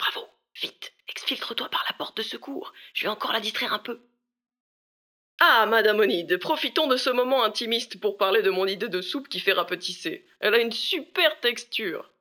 VO_LVL3_EVENT_Bravo reussite mission_03.ogg